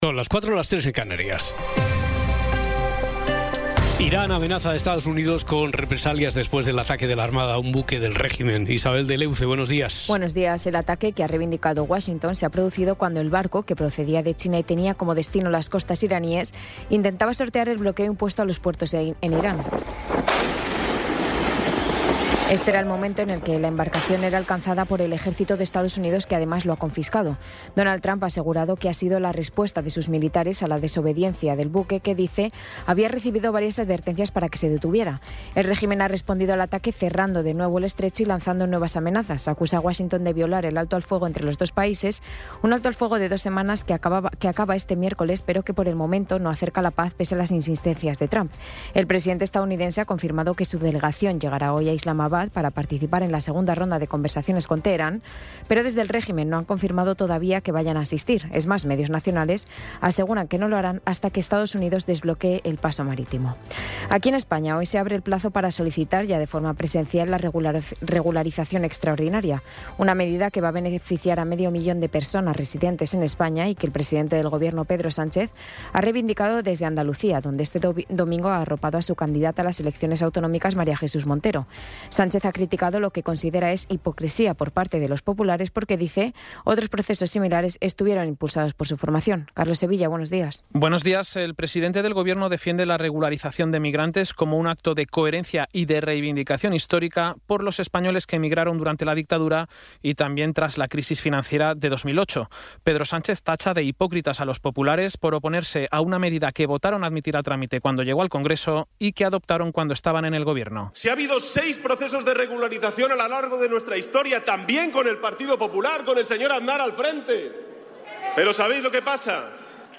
Resumen informativo con las noticias más destacadas del 20 de abril de 2026 a las cuatro de la mañana.